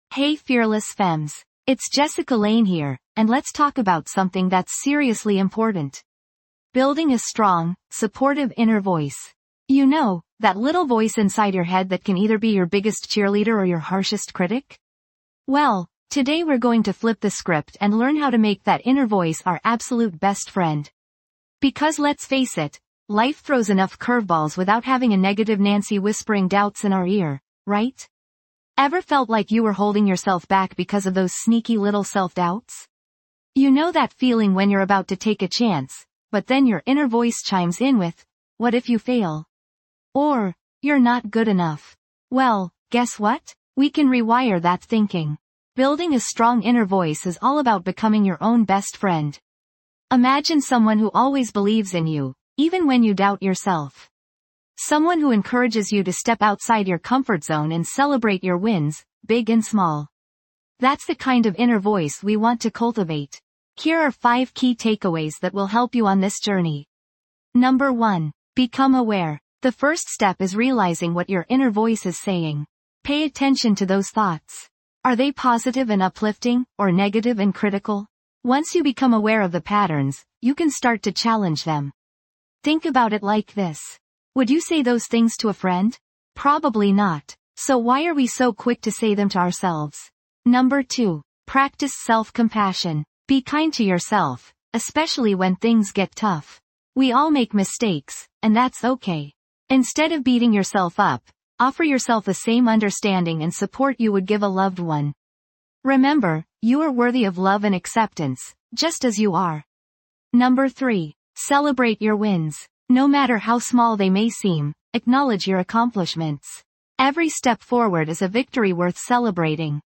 Podcast Category:. Self-Improvement, Personal Development, Motivational Talks Subscribe to Fearless Femme 5-Minute Daily Motivation, and Share the podcast with your friends, and bring more mindfulness, energy, and positivity into your life every day.
This podcast is created with the help of advanced AI to deliver thoughtful affirmations and positive messages just for you.